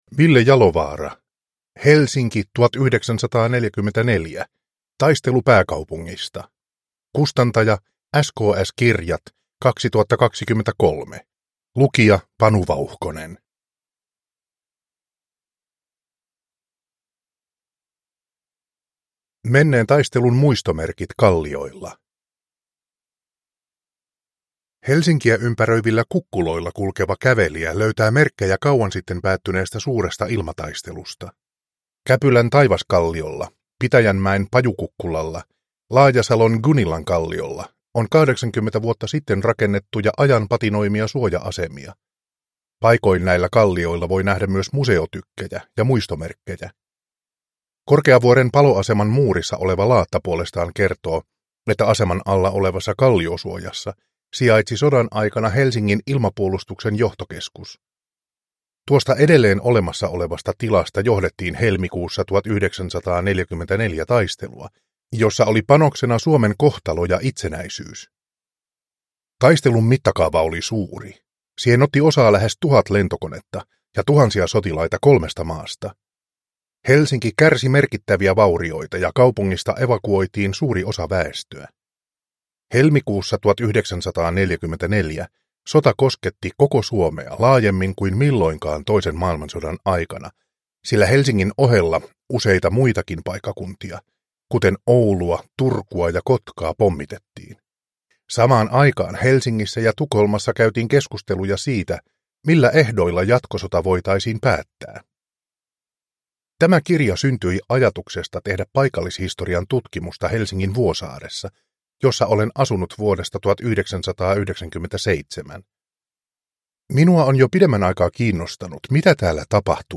Helsinki 1944 – Ljudbok – Laddas ner